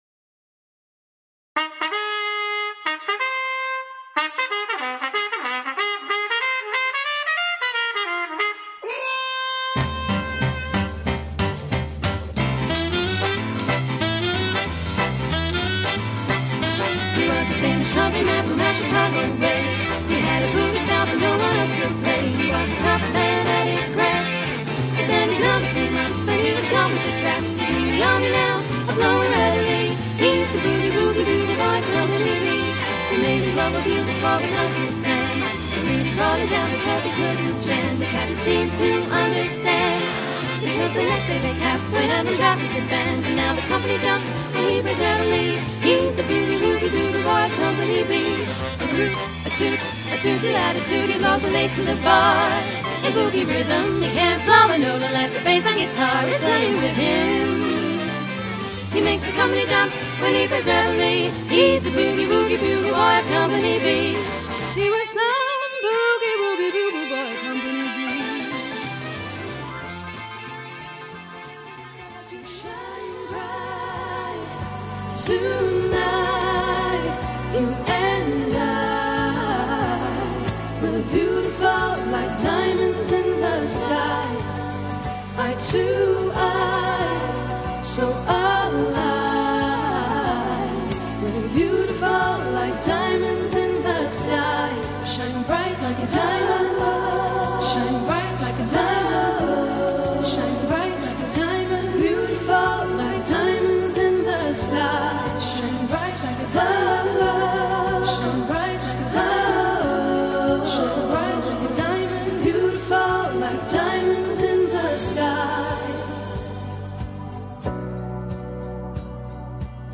Great songs, Great energy, Great voices
a stunning all female trio group